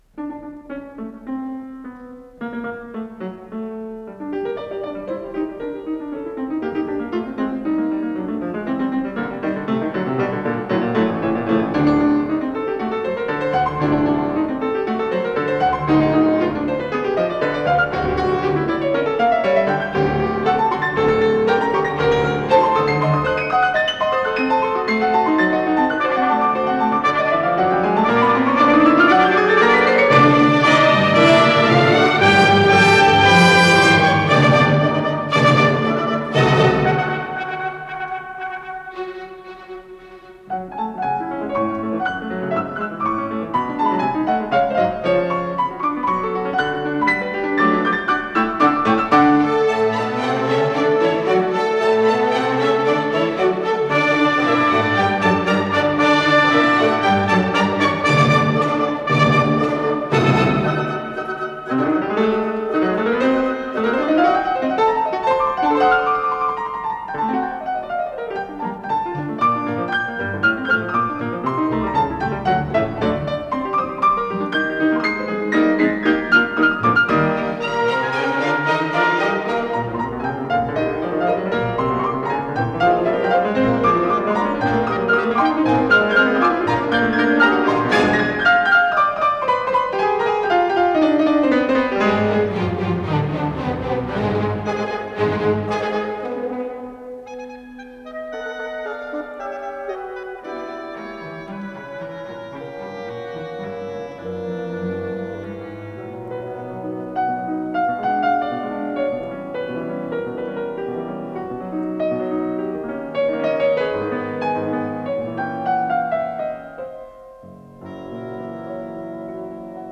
ДКС-17033 — Концерт для фортепиано и оркестра — Ретро-архив Аудио
фортепиано
соль минор